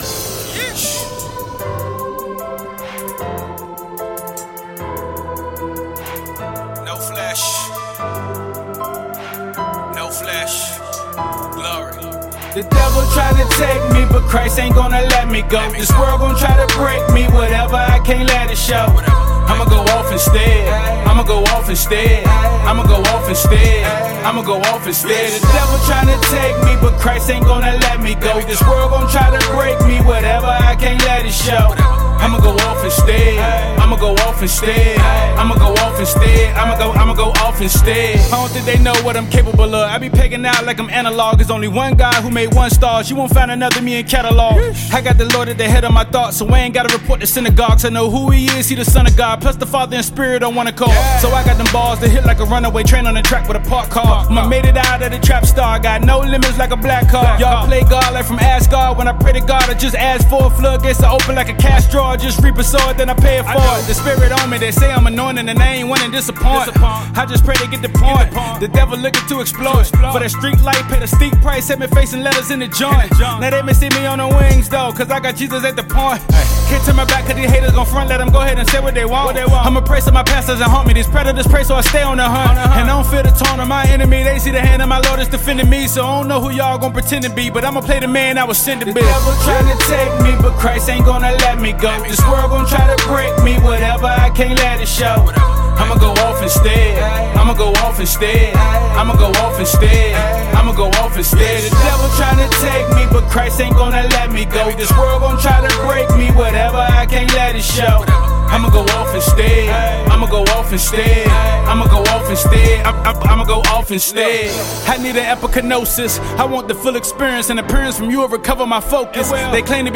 Christian Hip Hop
Christian rapper